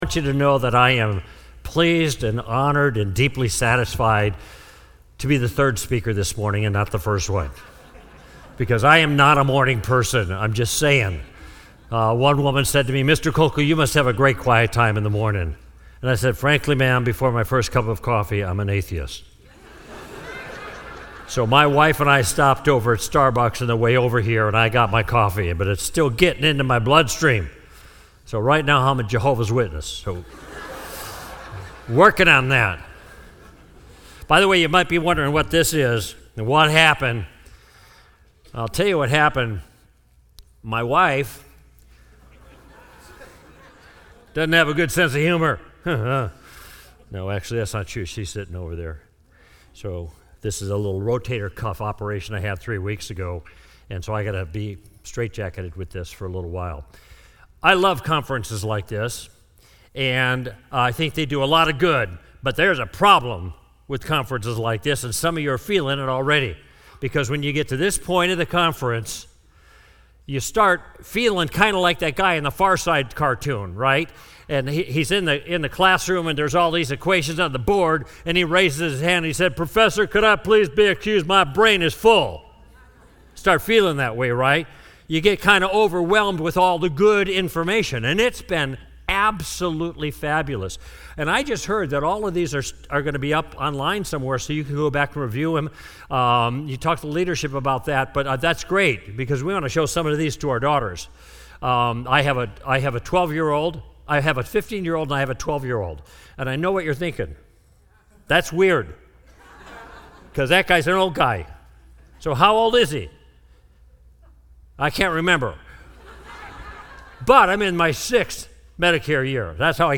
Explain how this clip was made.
Insight Is 2020: Apologetics Conference